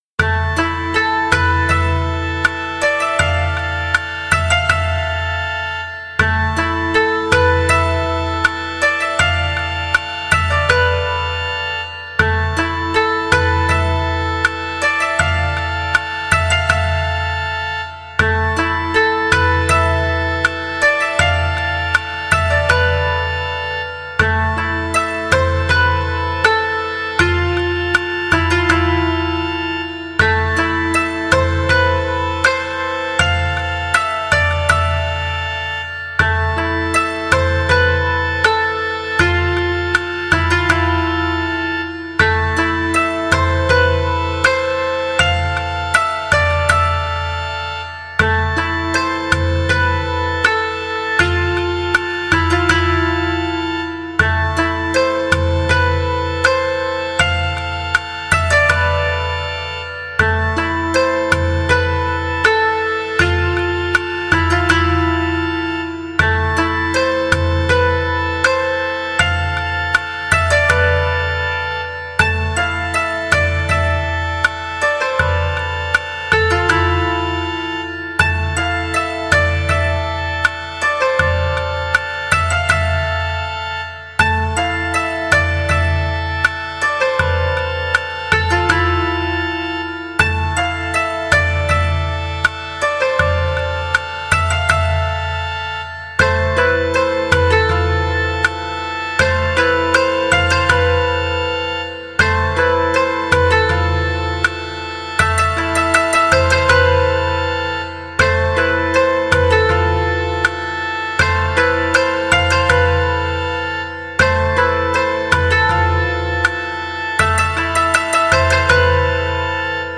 和風のゆったりした曲です。【BPM80】